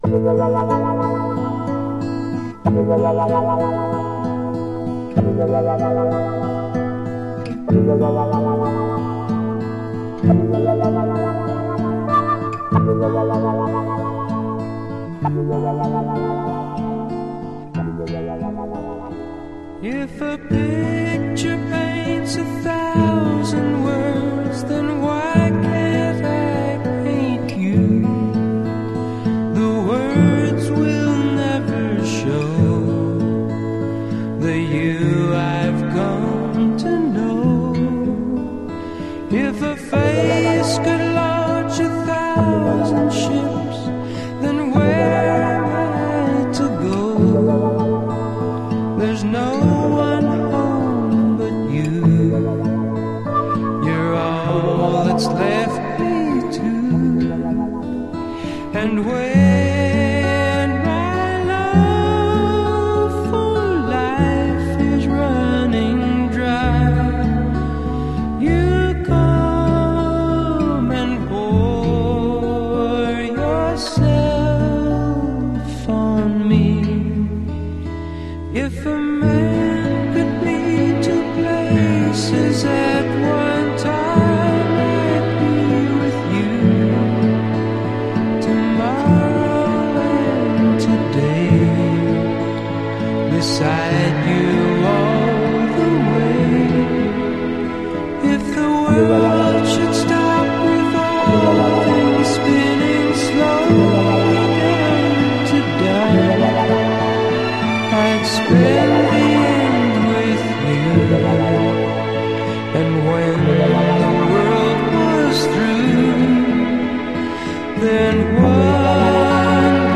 And the arrangement suits the mood perfectly.